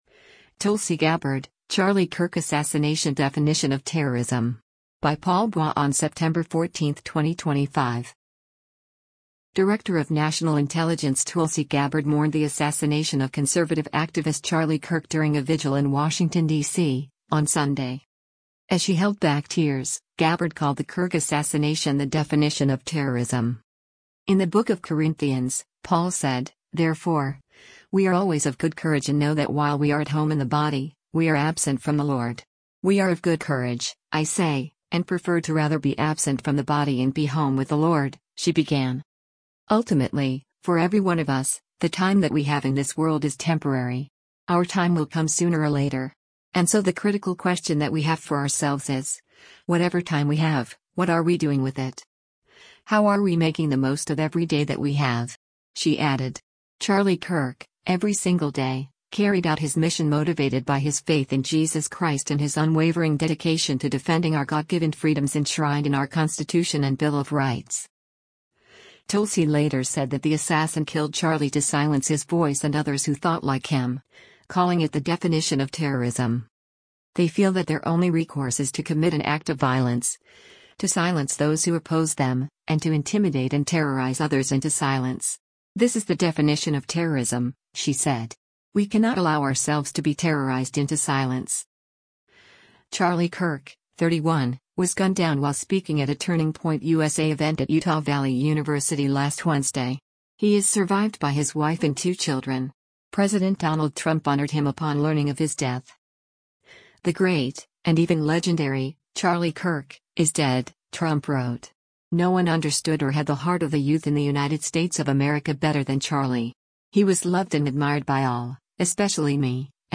Director of National Intelligence Tulsi Gabbard mourned the assassination of conservative activist Charlie Kirk during a vigil in Washington, DC, on Sunday.
As she held back tears, Gabbard called the Kirk assassination the “definition of terrorism.”